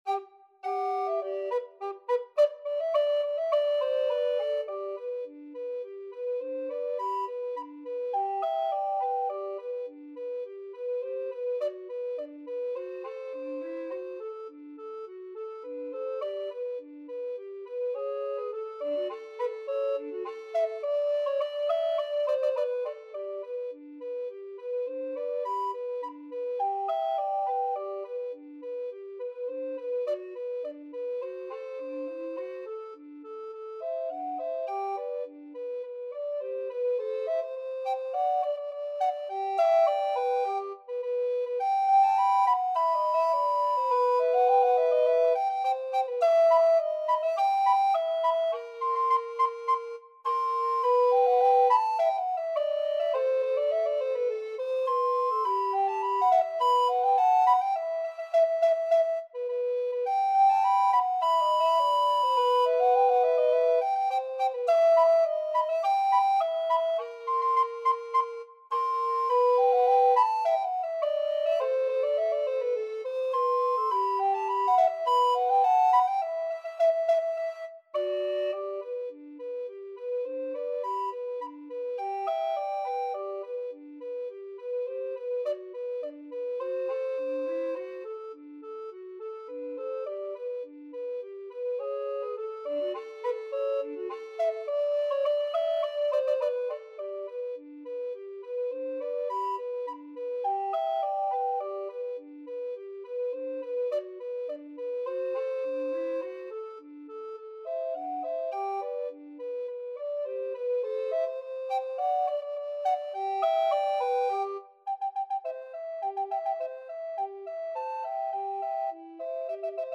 Alto RecorderTenor Recorder
2/2 (View more 2/2 Music)
Quick March = c.104
Classical (View more Classical Recorder Duet Music)